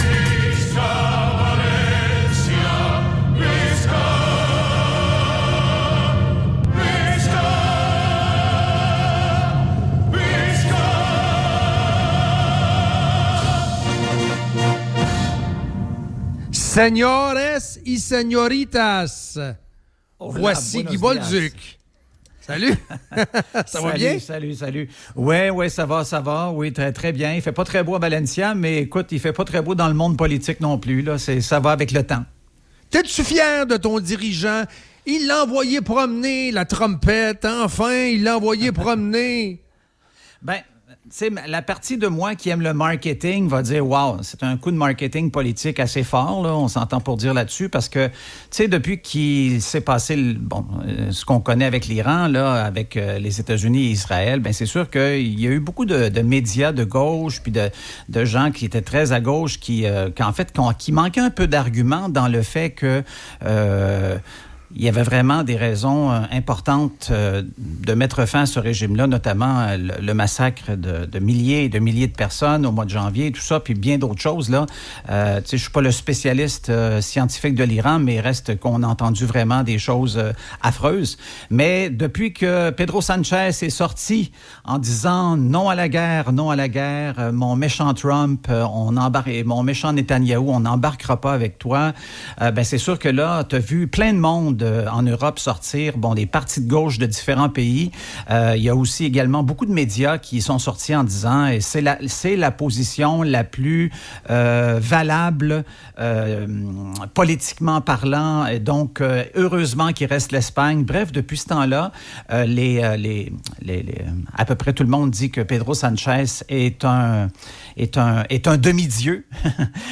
en direct d'Espagne